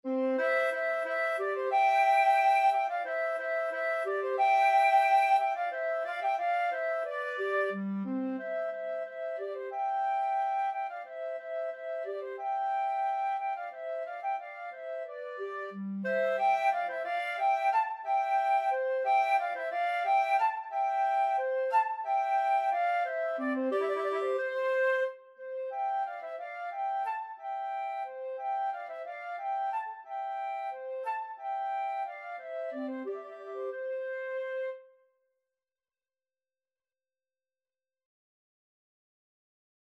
=180 Vivace (View more music marked Vivace)
4/4 (View more 4/4 Music)
Classical (View more Classical Flute-Clarinet Duet Music)